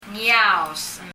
» day after tomorrow あさって、明後日 ngiaos [ŋi(y)aɔs] 英） day after tomorrow 日） あさって、明後日 なんか、ほぼ完璧に「猫語」 Leave a Reply 返信をキャンセルする。